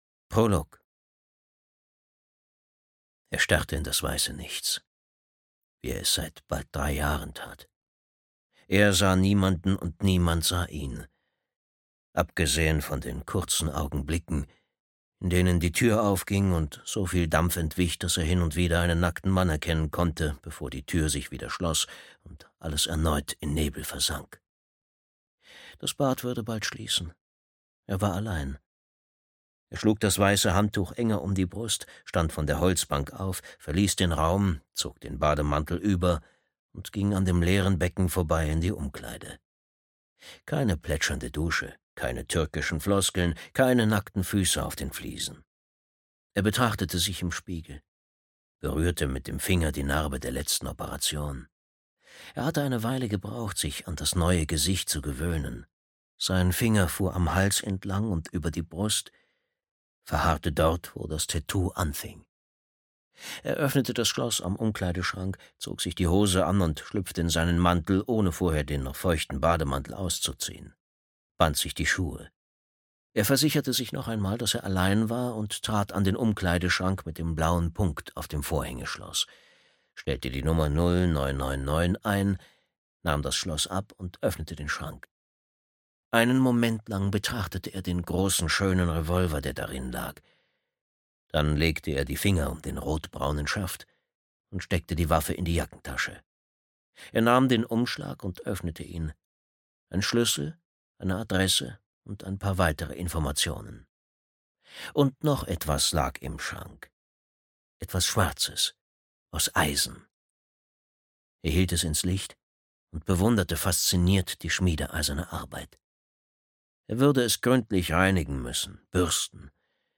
Durst (DE) audiokniha
Ukázka z knihy